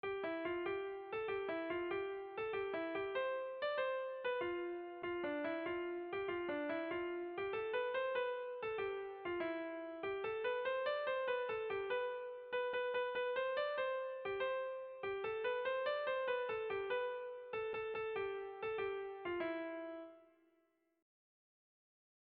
Irrizkoa
Zortziko handia (hg) / Lau puntuko handia (ip)
A1A2B1B2